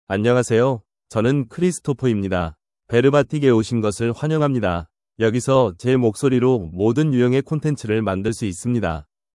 ChristopherMale Korean AI voice
Christopher is a male AI voice for Korean (Korea).
Voice sample
Male
Christopher delivers clear pronunciation with authentic Korea Korean intonation, making your content sound professionally produced.